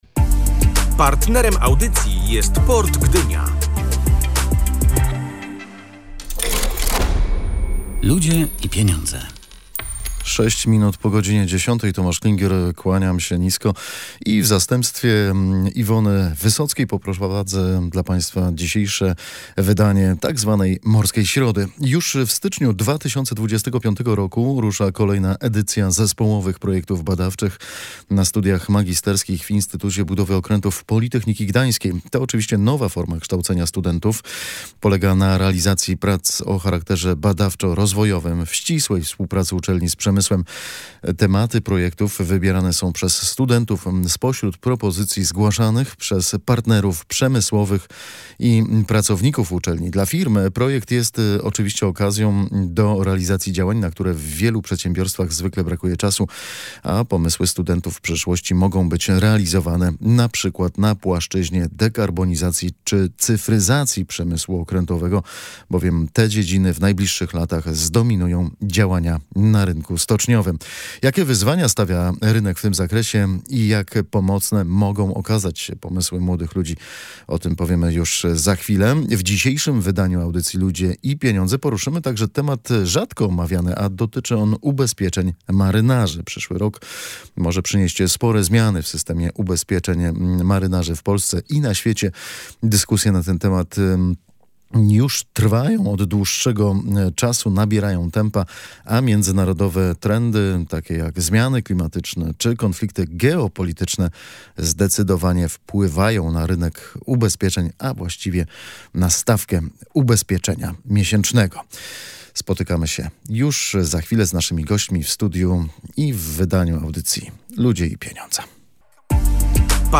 O tym rozmawialiśmy w audycji „Ludzie i Pieniądze”.